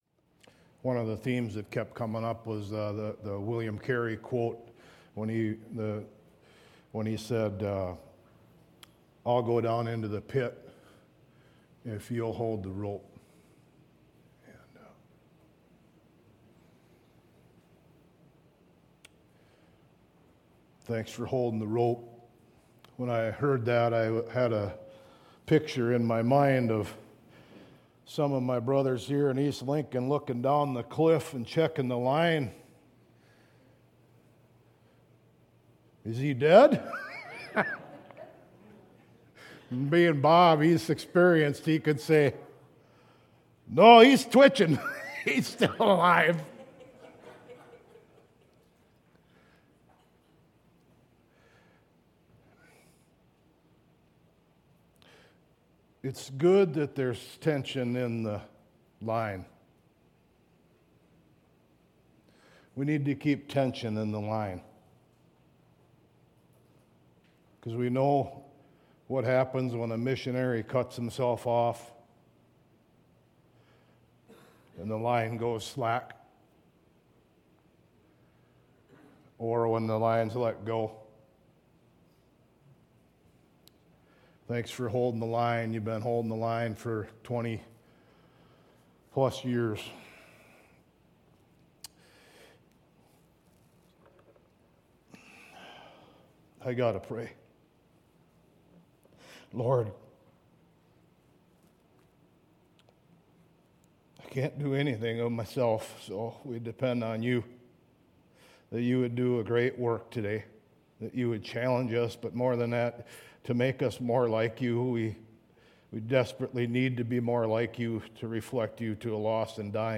2025 Learning to Love Pastor